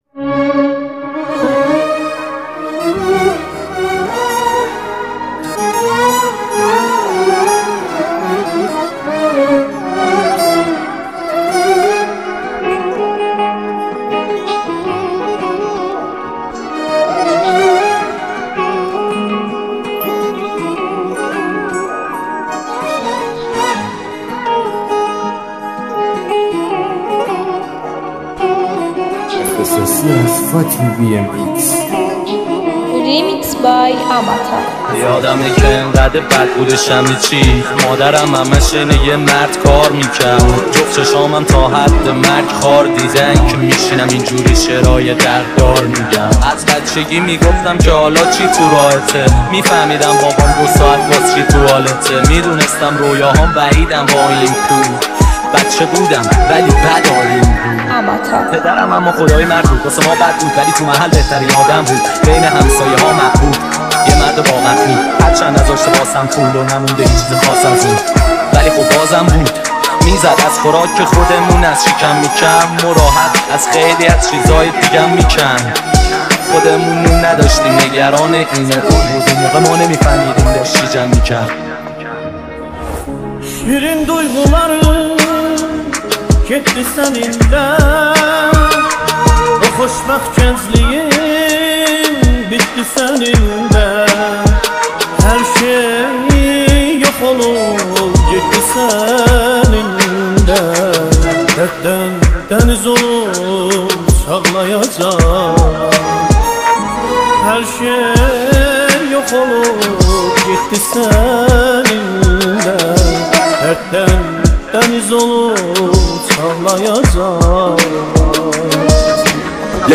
ریمیکس رپی
ریمیکس جدید رپ